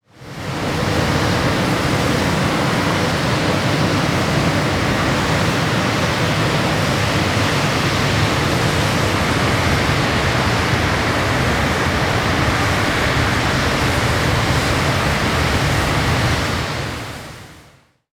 The sound of a heavy snowstorm - Eğitim Materyalleri - Slaytyerim Slaytlar